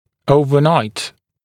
[ˌəuvə’naɪt][ˌоувэ’найт]всю ночь, по ночам